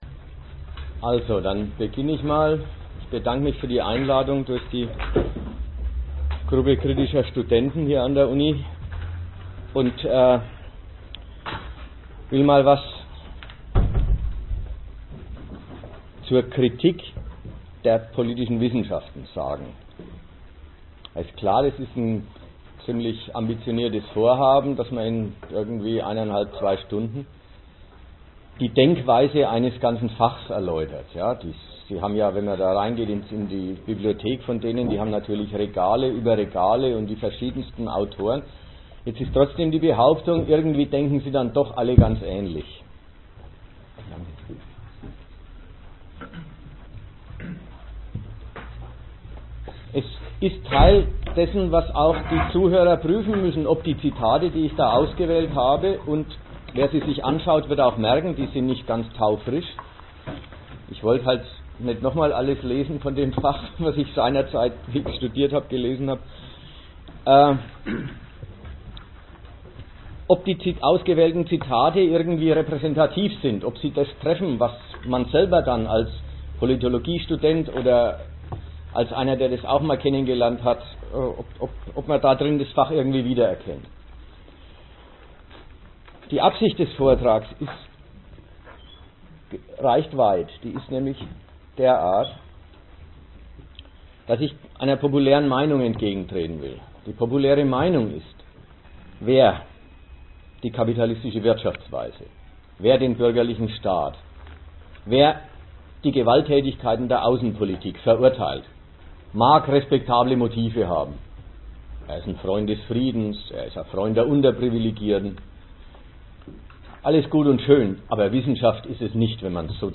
Dass in diesem universitären Fach nicht nur parteilich, sondern auch falsch nachgedacht wird, wird der Vortrag im Detail aufzeigen.